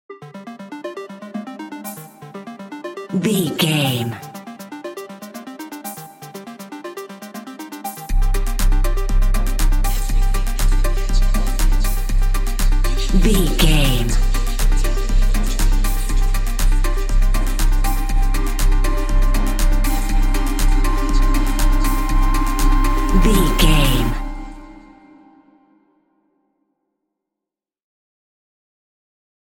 Epic / Action
Fast paced
Aeolian/Minor
Fast
dark
futuristic
groovy
aggressive
synthesiser
drum machine
vocals
house
electro dance
techno
trance
synth leads
synth bass
upbeat